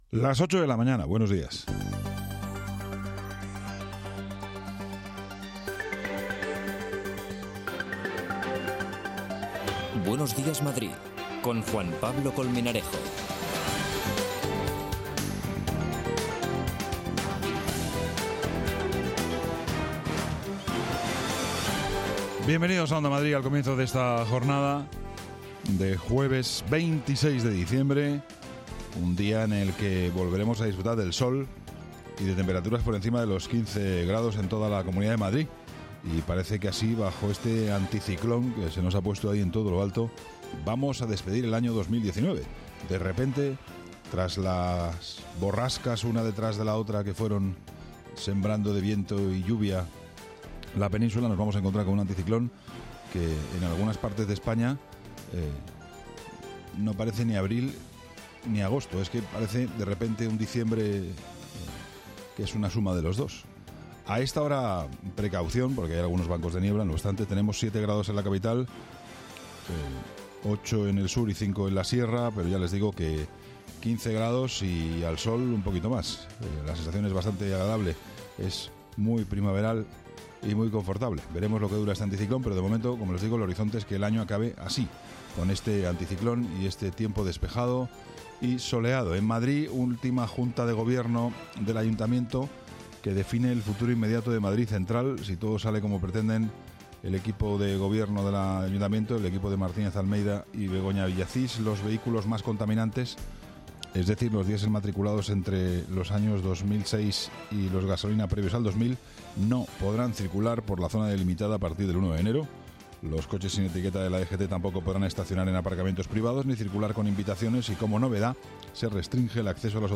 Actualidad, opinión, análisis, información de servicio público, conexiones en directo, entrevistas. Todo lo que necesitas para comenzar el día, desde el rigor y la pluralidad informativa.